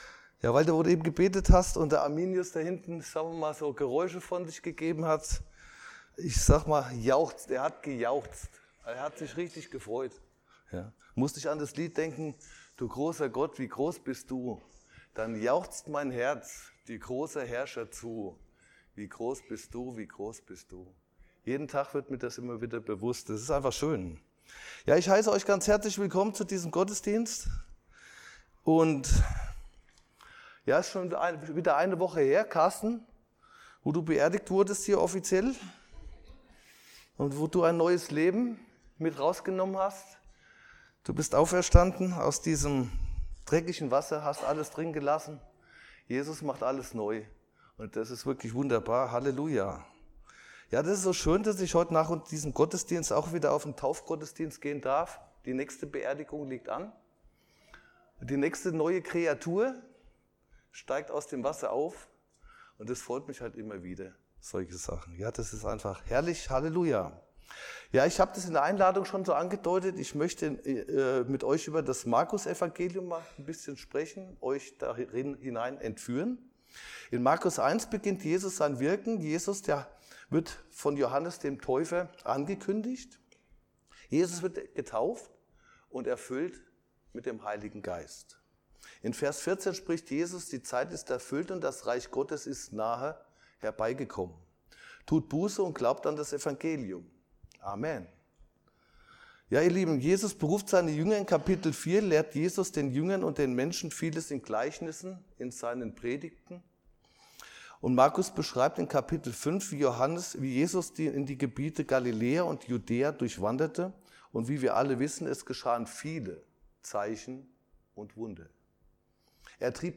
Markus 6 1-6 Dienstart: Predigt In Markus 6,1–6 kehrt Jesus in seine Vaterstadt Nazareth zurück und lehrt in der Synagoge.